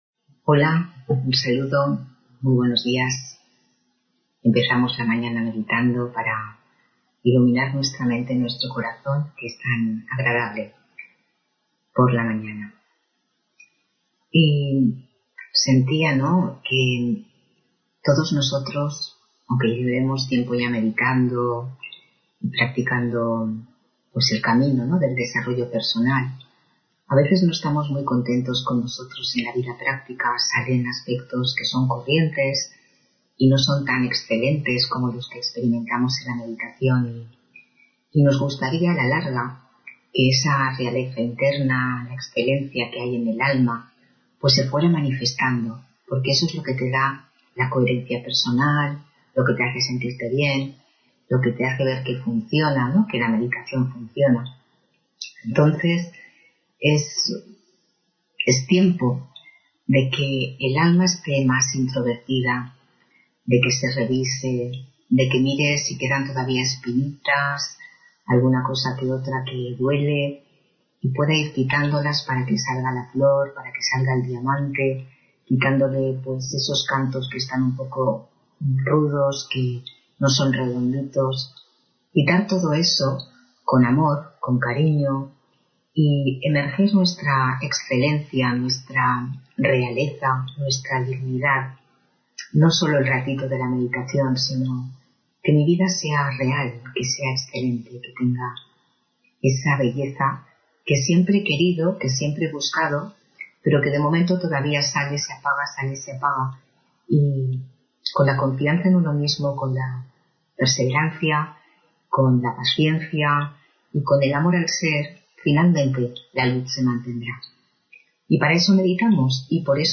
Meditación de la mañana: Una mente abierta